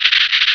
Cri de Séviper dans Pokémon Rubis et Saphir.